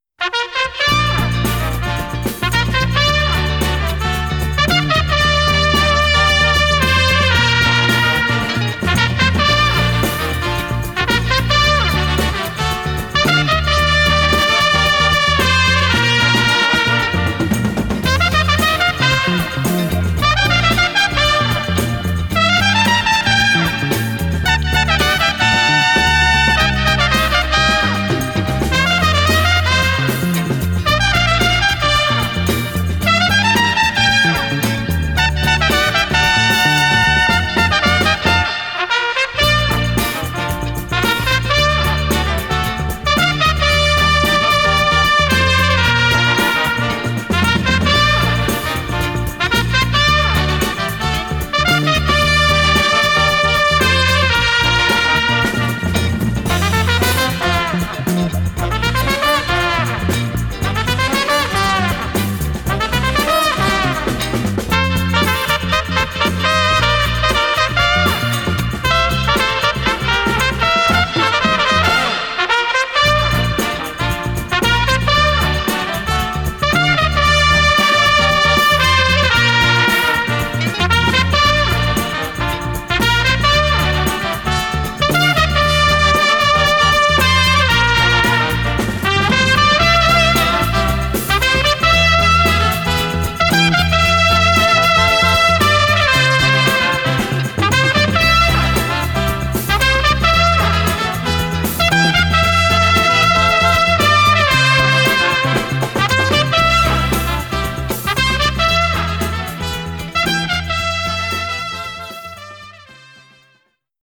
Французский трубач, руководитель оркестра.